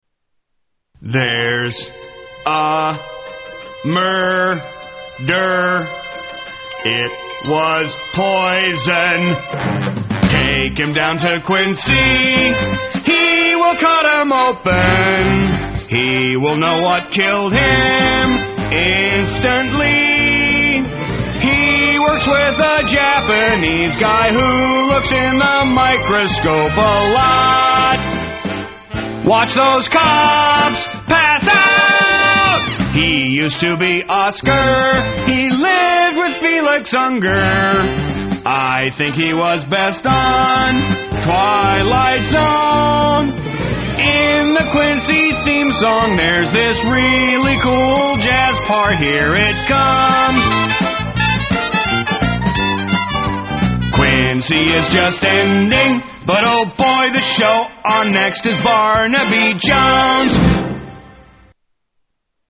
Tags: Media More Parodies Clips Parodies Songs Comedy Spoofs